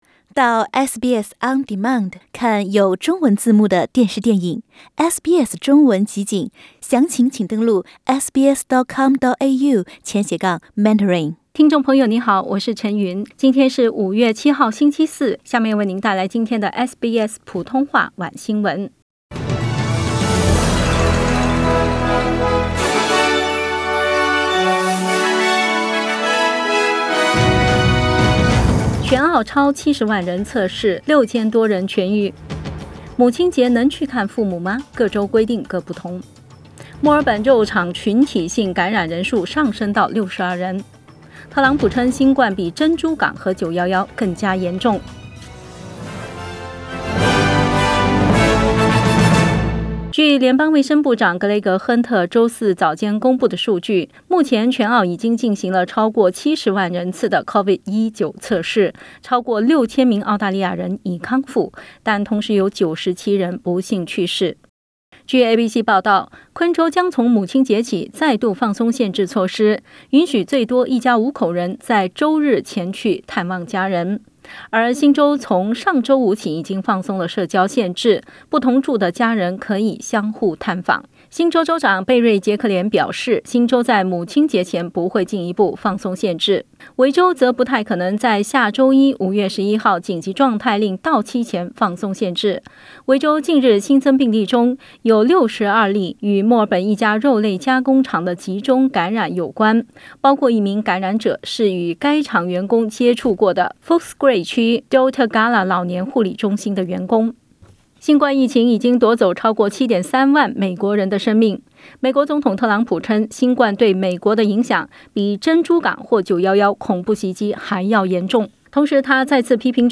SBS晚新闻（5月7日）